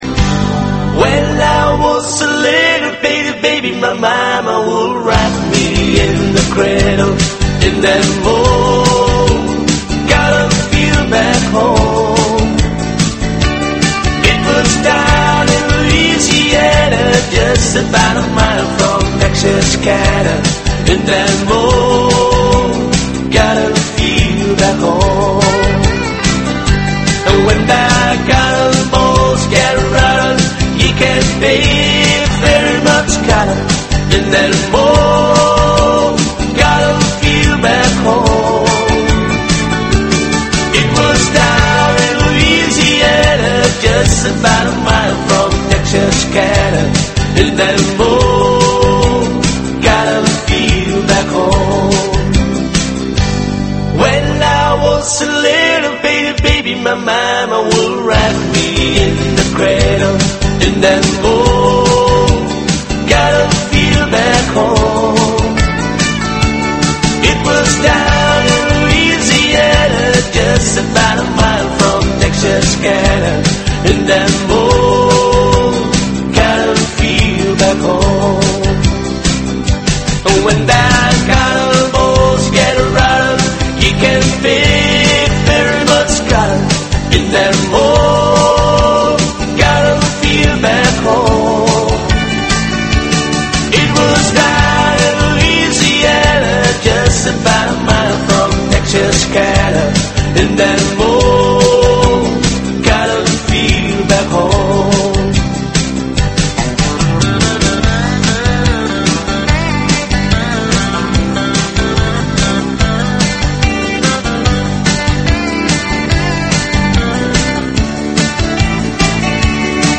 美国乡村音乐:Cotton Fields 听力文件下载—在线英语听力室